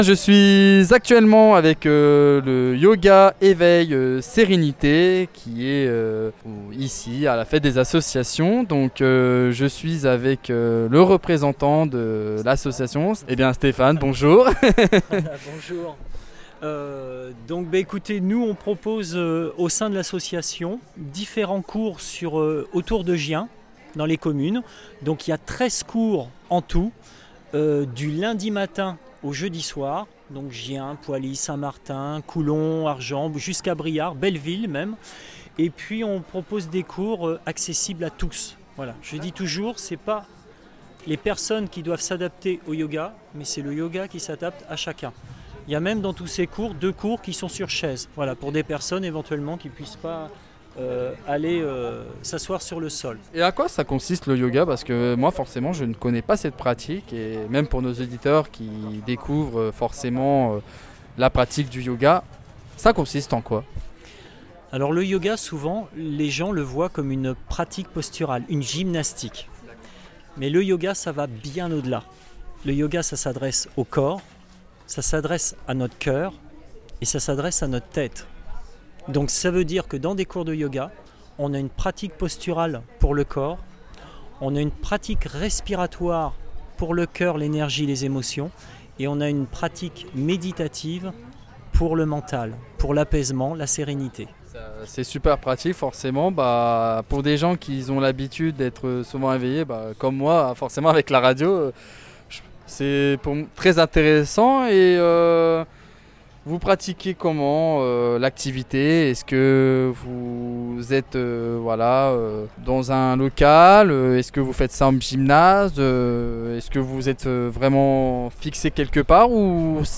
Fête des associations de Gien 2025 - Yoga Eveil Sérénité